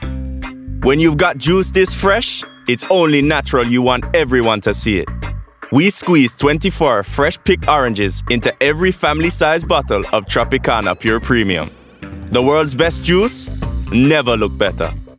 RP ('Received Pronunciation')
contemporary, versatile, confident